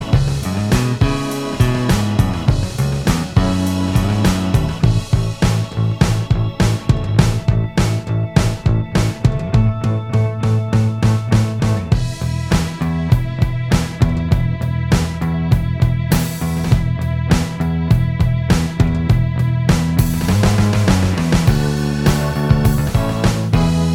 Minus Guitars Pop (1990s) 3:48 Buy £1.50